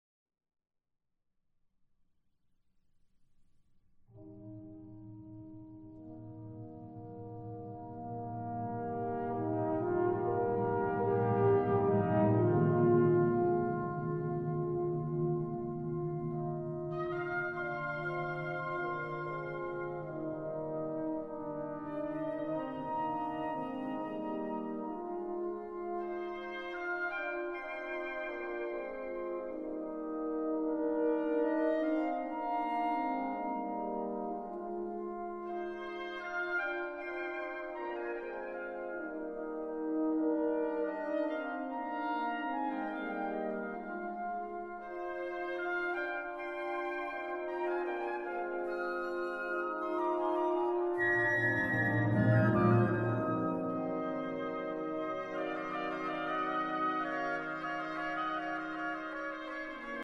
Great Classical Music